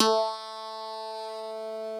genesis_bass_045.wav